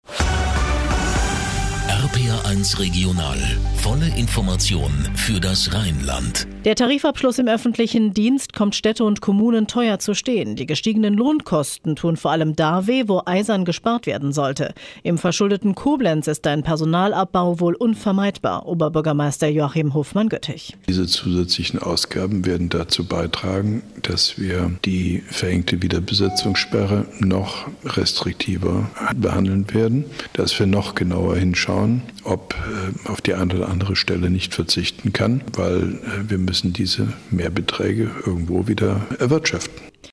Ausschnitt: RPR1 Regional, Informationen für das Rheinland, Studio Koblenz, 8.30 Uhr ff.,  05.04.2012
Mit einem Kurzinterview von OB Hofmann-Göttig